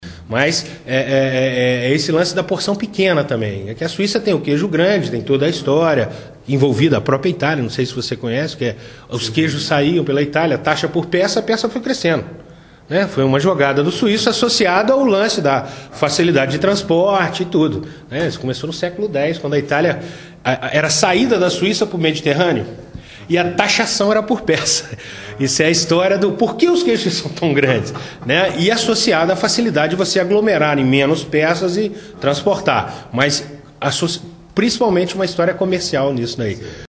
O mestre-queijeiro explica porque o queijo suíço é tão grande